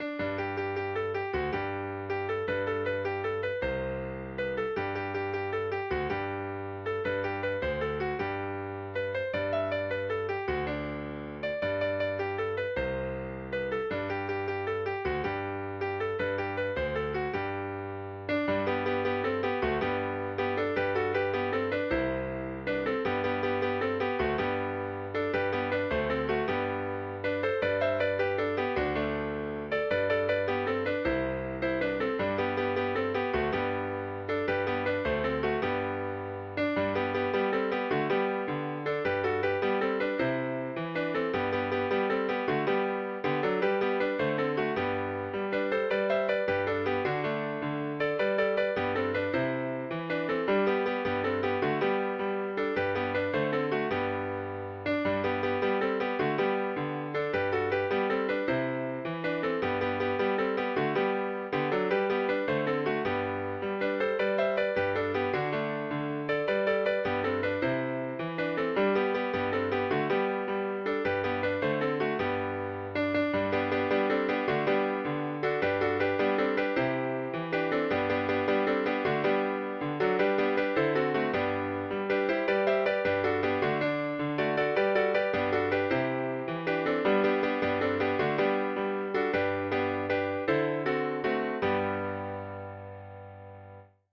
This ballad was collected in Northern Ireland by Sean O'Boyle and Peter Kennedy in 1952. The tune is a traditional jig.